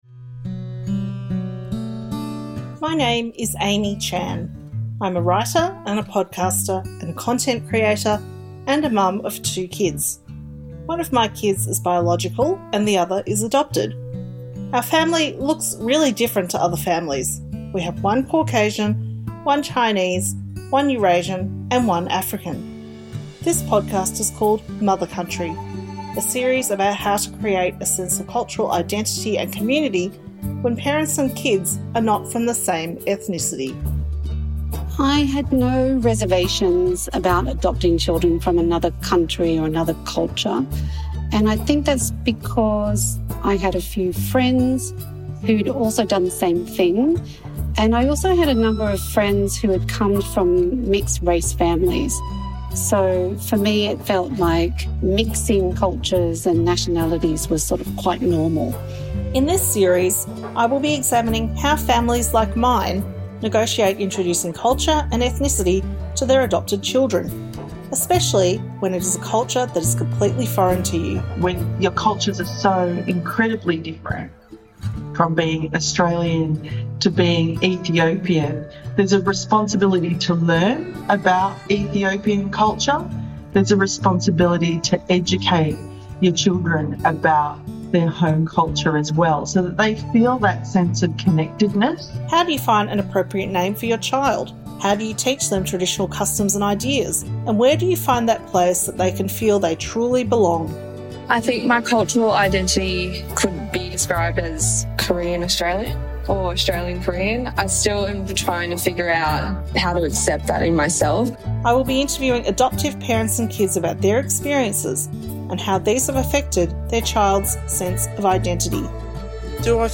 Hear from adoptive parents and children with Korean, Ethiopian, Colombian, Cambodian and Cook Islands heritage as they share thoughts on the complexities of intercultural adoption, and how they help their kids understand their birth culture.